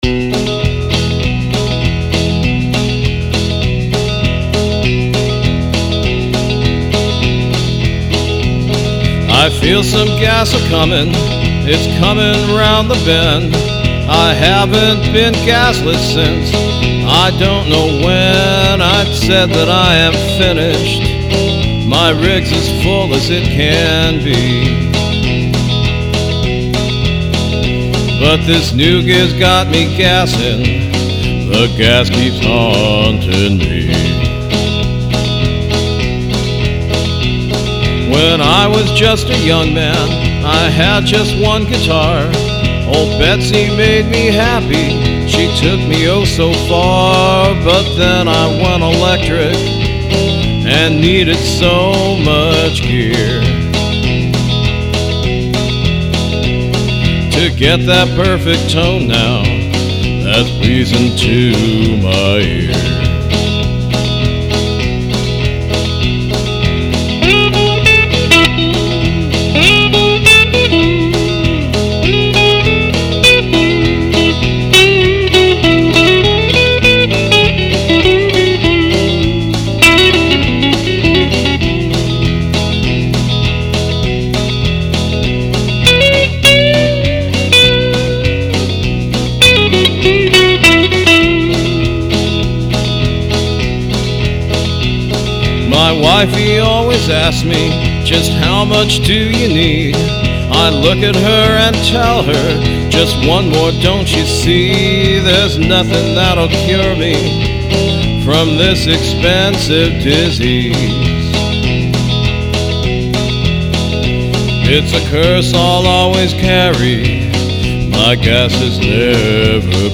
After I was done I recorded the following tune: